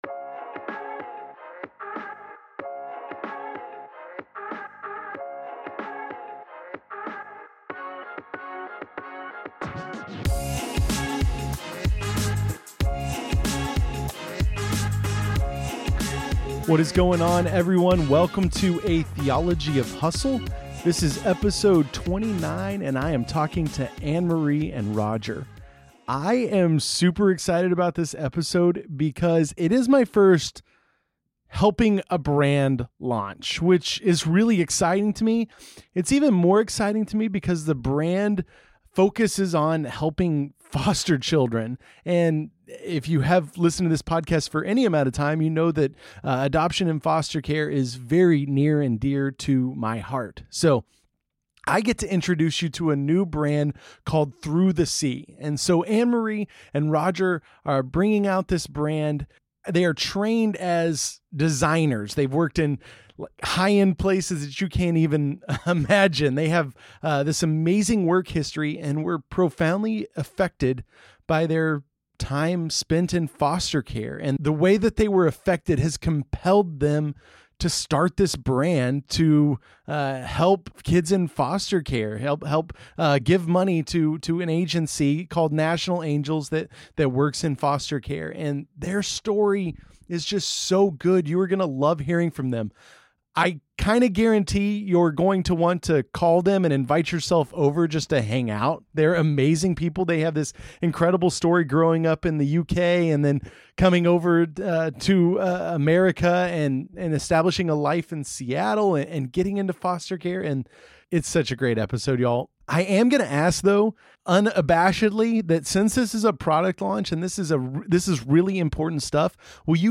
[ep 29] This interview